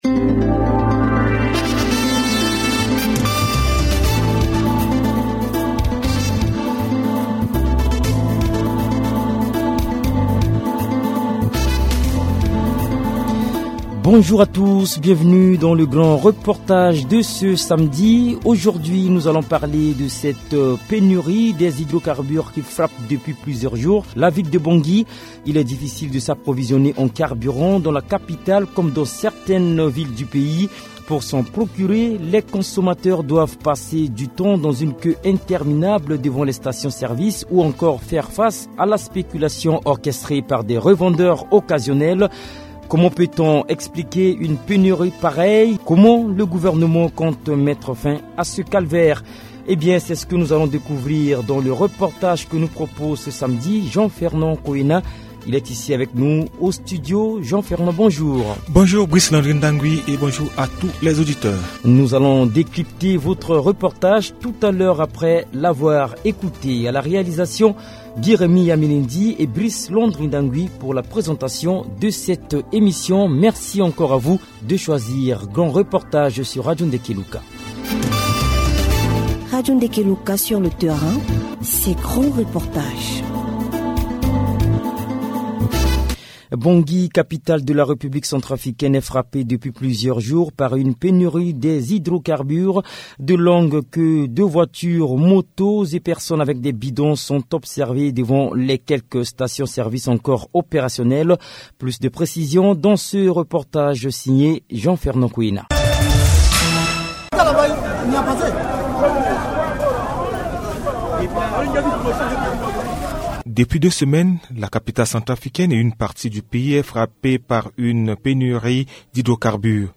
Dans ce reportage terrain de Radio Ndeke Luka, les consommateurs, leaders d’opinion et décideurs donnent leur lecture de la crise et engagent l’ASRP à faire son travail.